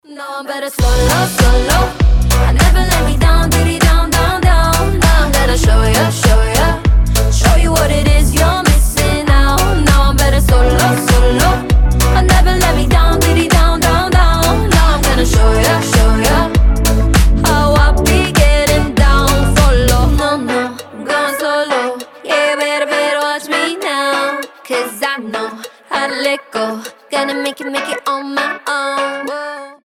• Качество: 320, Stereo
заводные